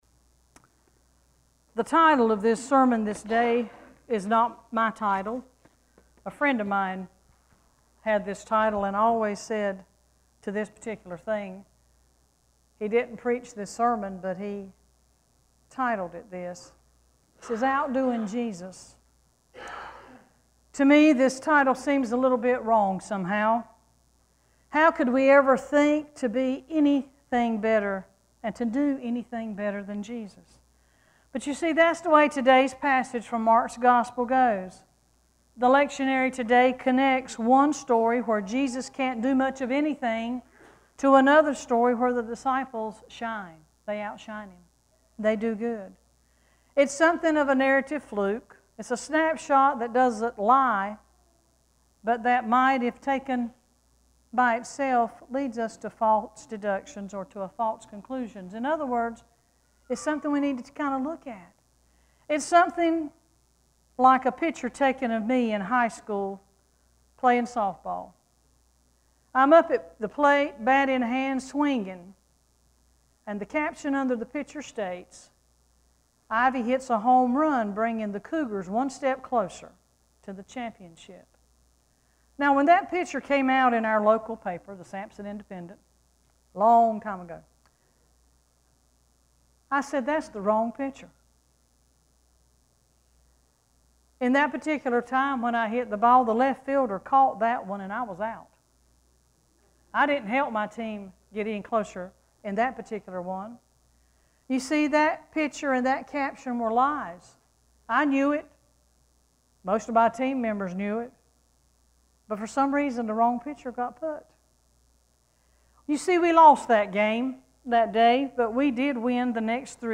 7-5-sermon.mp3